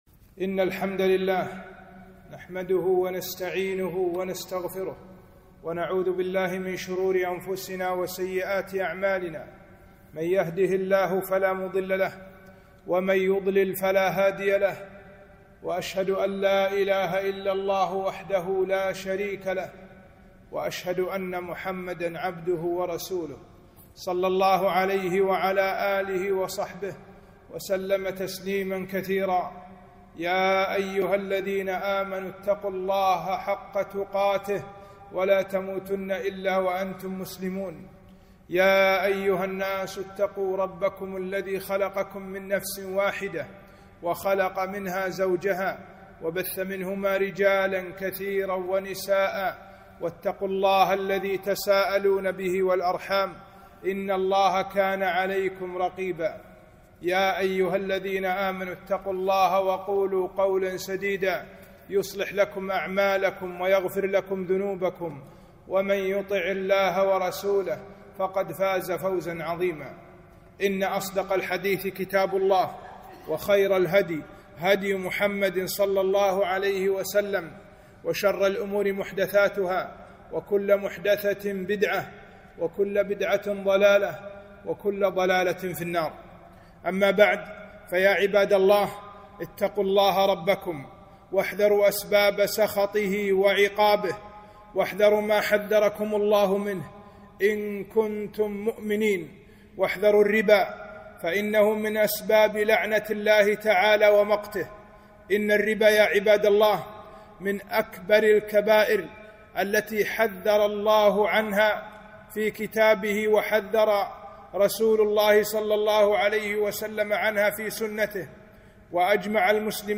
خطبة - خطر الربا على الفرد والمجتمع